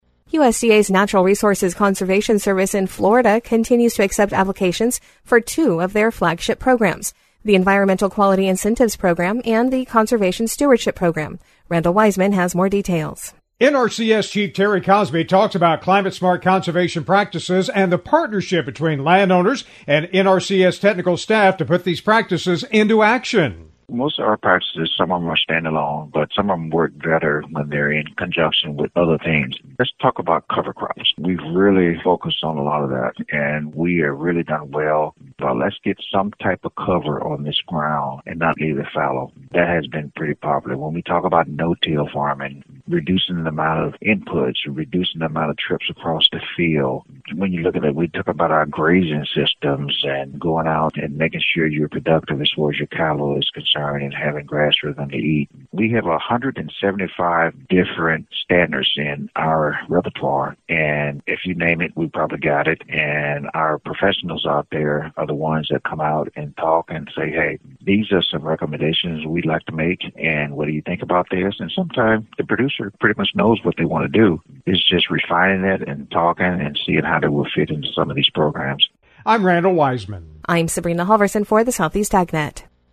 NRCS Chief Terry Cosby talks about climate smart conservation practices and the partnership between landowners and NRCS technical staff to put these practices into action.